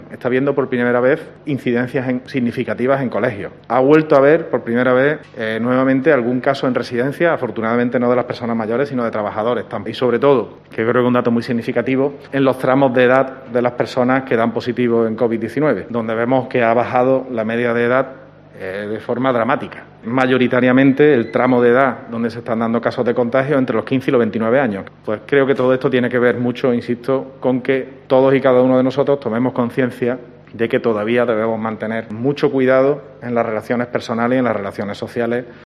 En una rueda de prensa